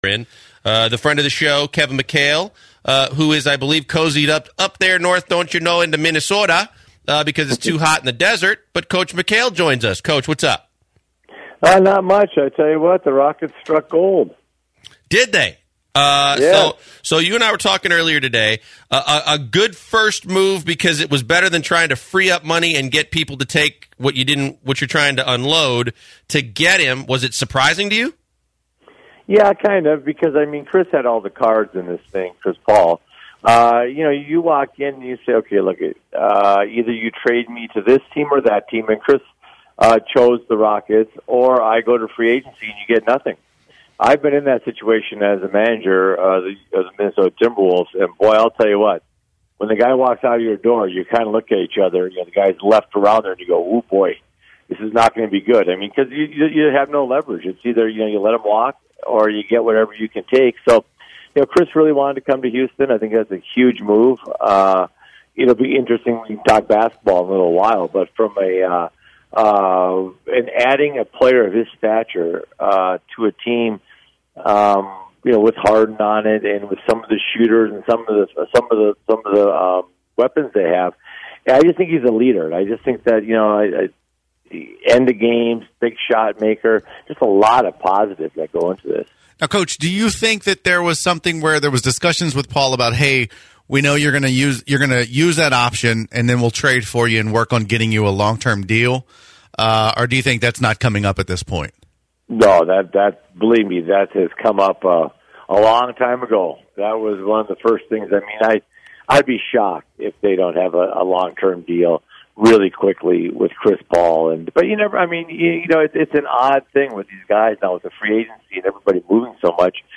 Kevin McHale interview with the Usual Suspects